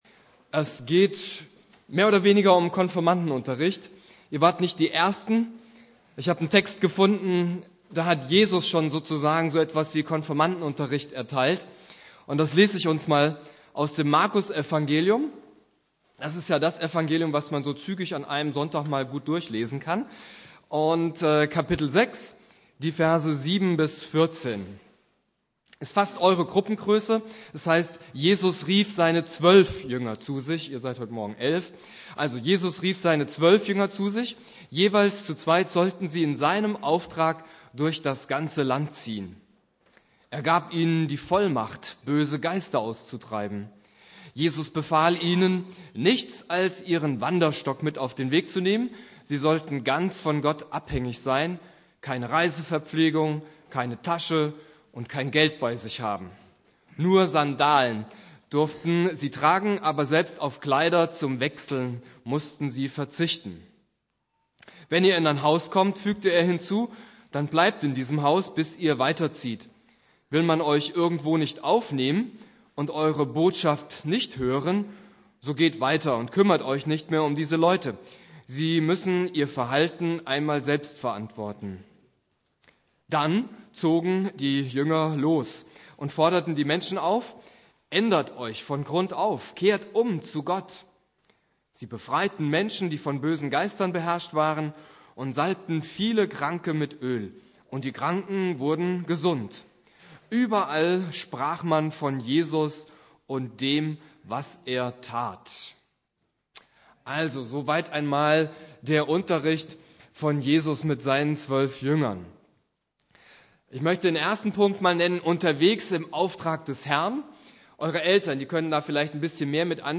Predigt
Unterwegs im Aufrag des Herrn (Konfirmationsgottesdienst) Bibeltext